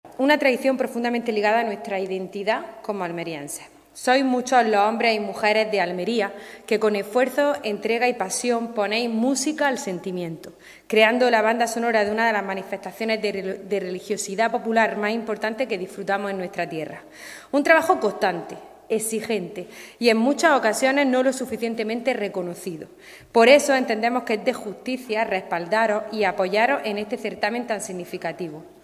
ALMUDENA-MORALES-DIPUTADA-CULTURA.mp3